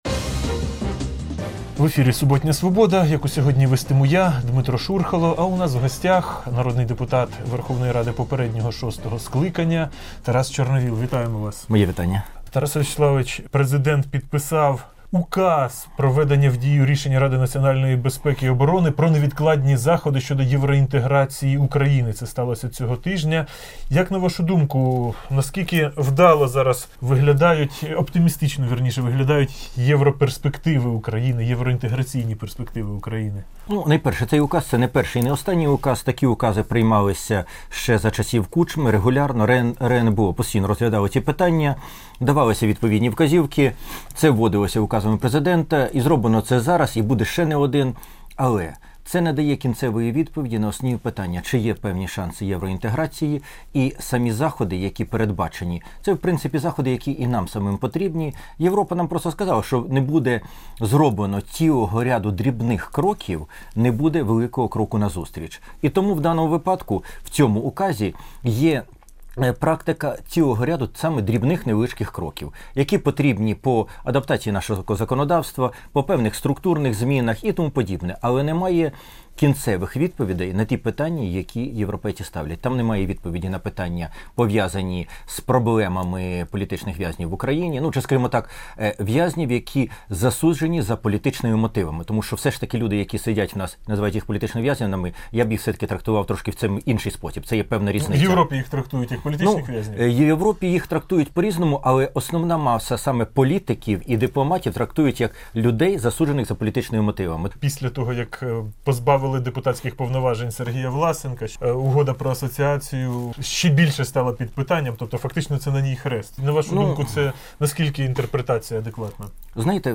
Суботнє інтерв’ю